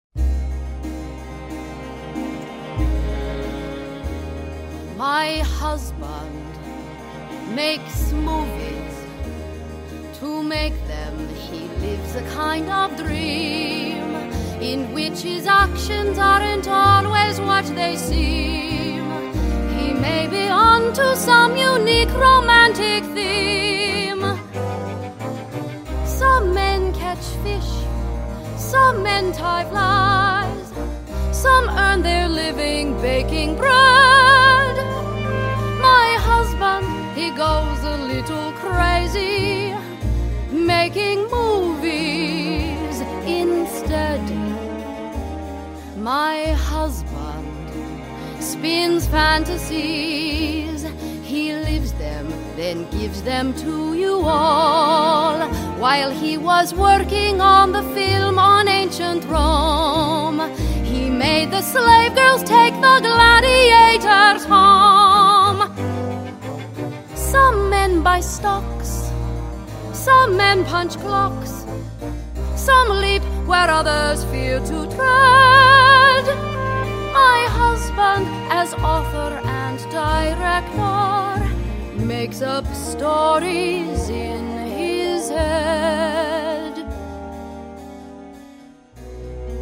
Low voices – female